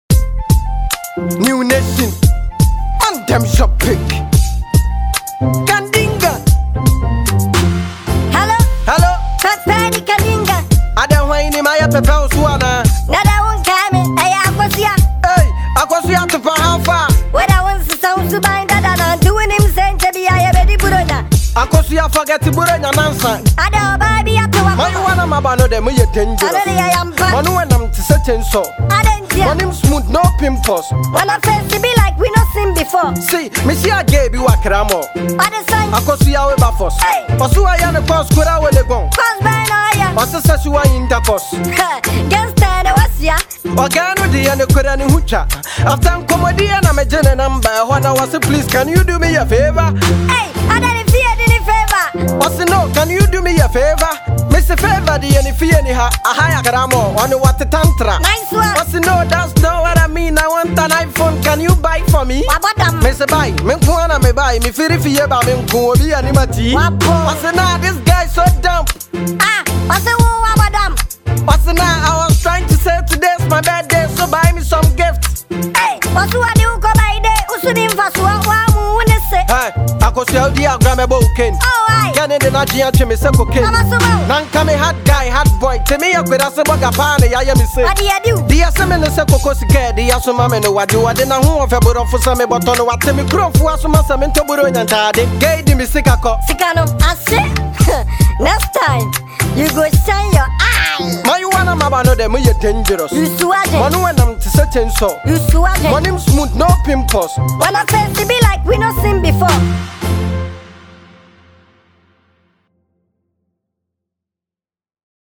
a talented Ghanaian rapper